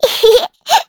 Taily-Vox_Happy1.wav